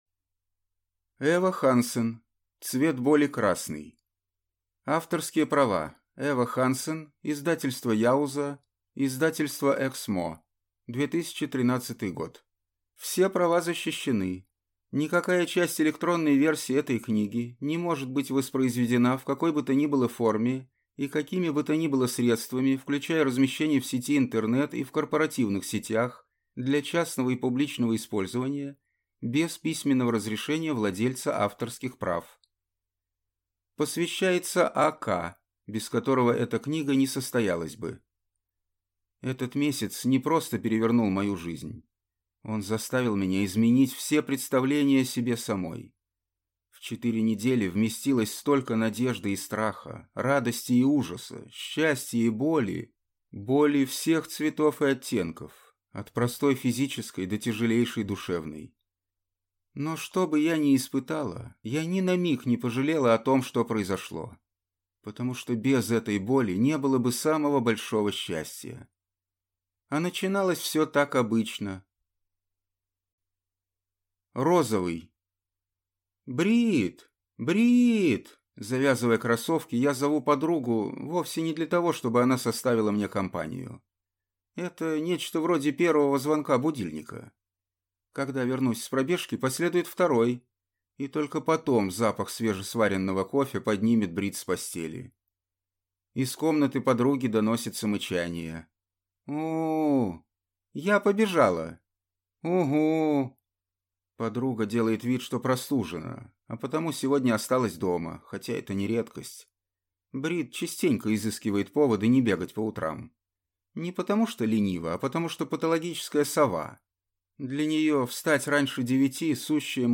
Аудиокнига Цвет боли: красный - купить, скачать и слушать онлайн | КнигоПоиск